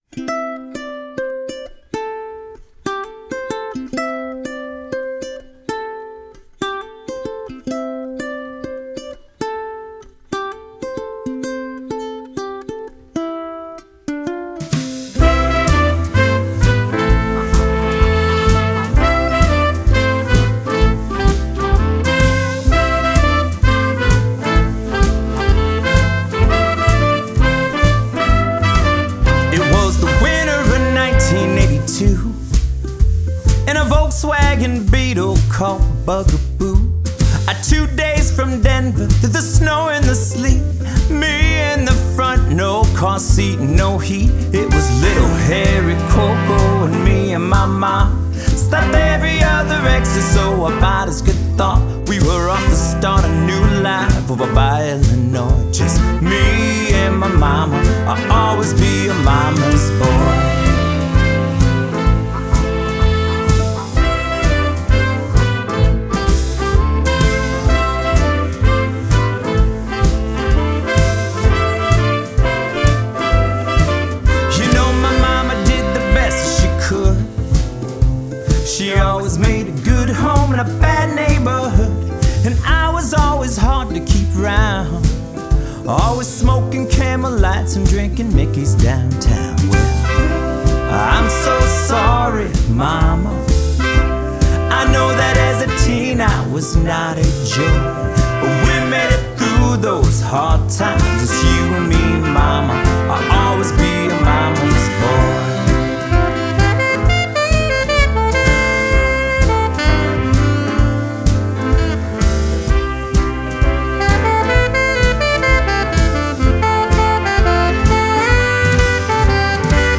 An upbeat Jazzy tune about being the child of a single Mom!